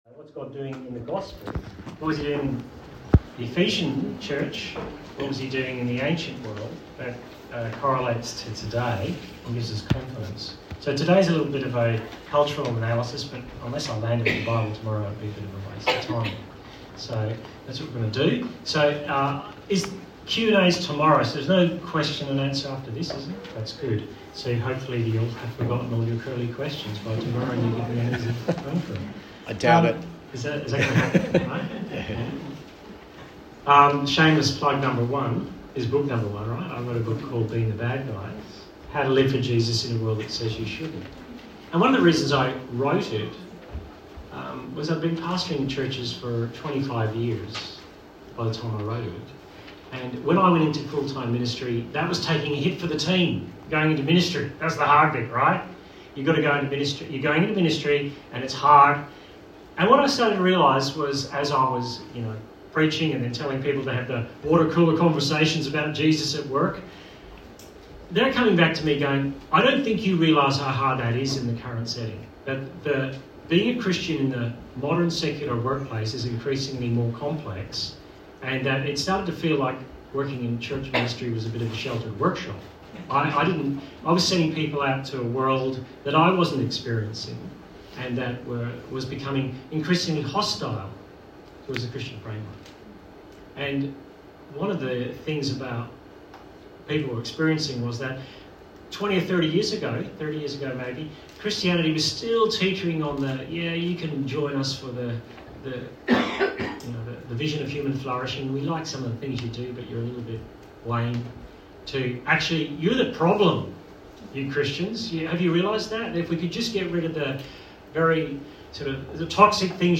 Church Camp Talk